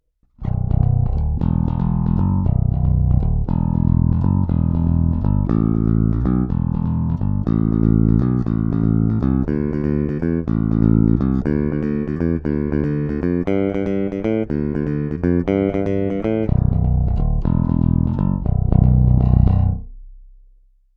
Oba snímače, oba dvojcívka, oba sériově (trsátko)